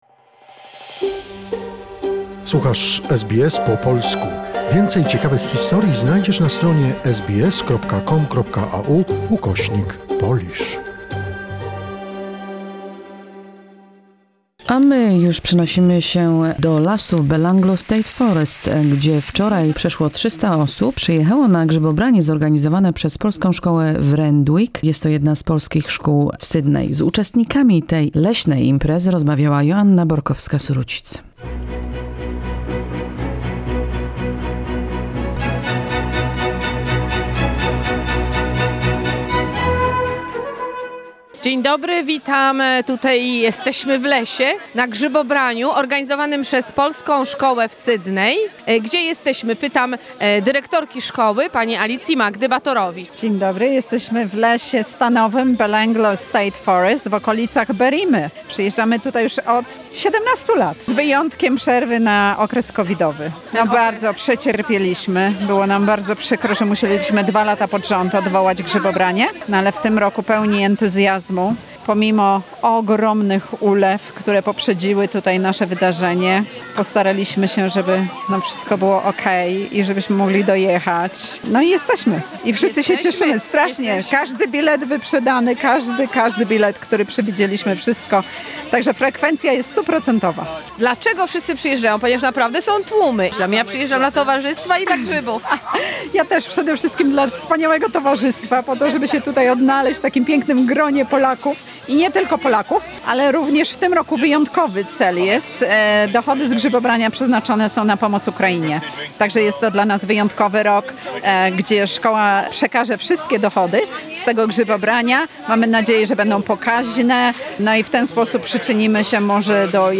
The reportage from the annual mushroom picking! Over 300 people came on Sunday, April 3, 2022 for mushroom picking organized by the Polish School in Sydney, Randwick. Lovers of the scent of forest fruits met in Belanglo State Forest.